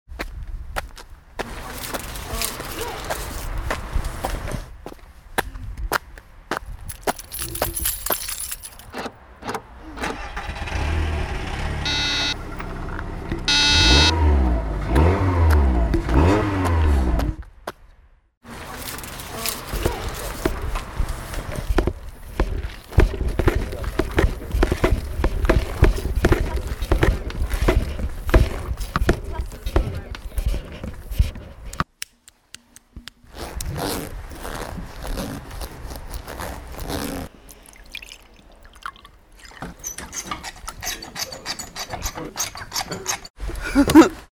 Total Normal Geräuschcollagen
Rund ums Radio war die Redaktion von Total Normal auf Geräusche-Fang und hat die selbst aufgenommenen Geräusche in einer Collage verarbeitet.
Die Schritte auf der Stahlrampe oder das Öffnen des Reisverschlusses nehmen wir im Alltag kaum mehr wahr. Aber als einzelnes Geräusch hört sich das Blätter rascheln oder die Hupe des E-Rollis ziemlich cool an.